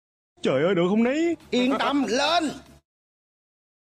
Thể loại: Câu nói Viral Việt Nam
Description: Âm thanh này thuộc dạng sound effect mp3 ngắn gọn, rất thích hợp để lồng tiếng video, edit clip hài hước, dựng cảnh cao trào hay thêm audio phụ cho các đoạn hội thoại chọc cười.